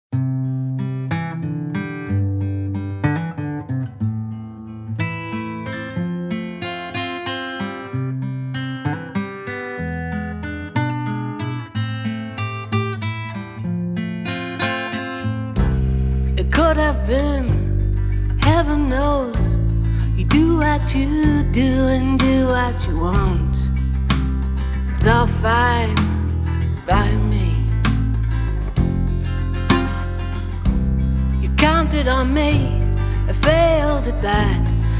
Жанр: Альтернатива / Фолк